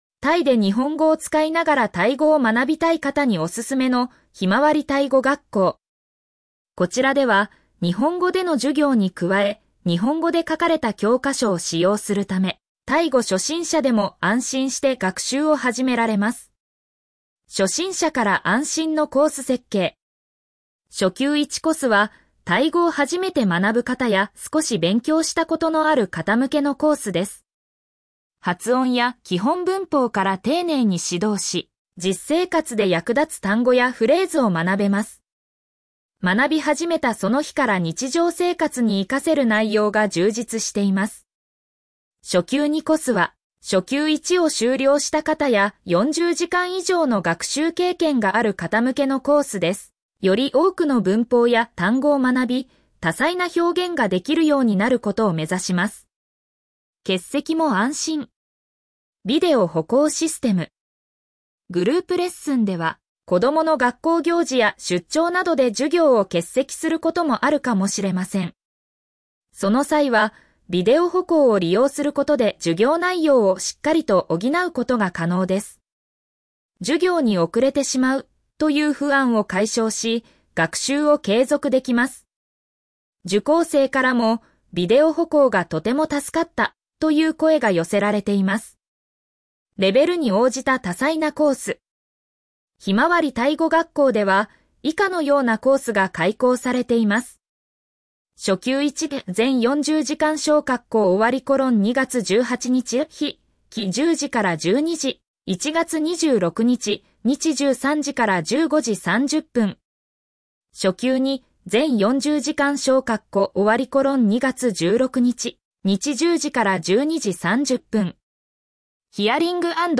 ペルプ バンコク・メディプレックスビルディング リンク先 「ひまわりタイ語学校」授業風景 グループ 「ひまわりタイ語学校」授業風景 個人 読み上げ タイで日本語を使いながらタイ語を学びたい方におすすめの「ひまわりタイ語学校」。